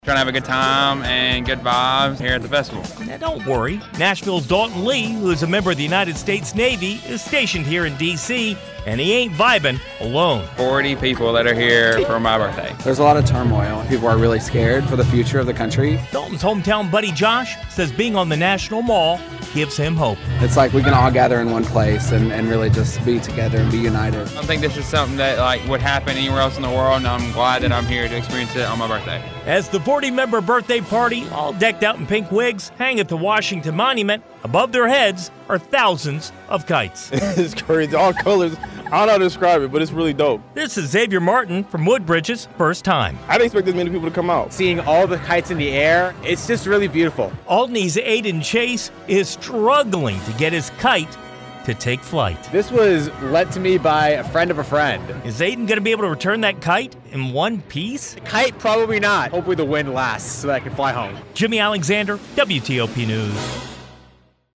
talked to visitors at the National Mall about what brought them to the Blossom Kite Festival.